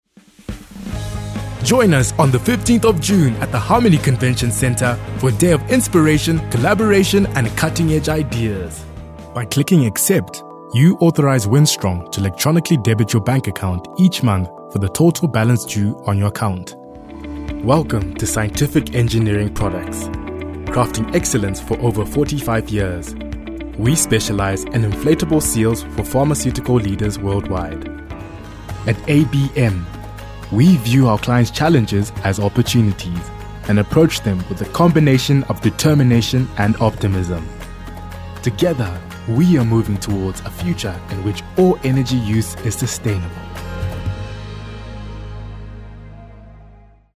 Corporate Videos
Rode NT1 Condenser Microphone
Young Adult
BaritoneHighLow
FriendlyConversationalYouthfulWarmApproachableNeutralKindPleasantIntriguing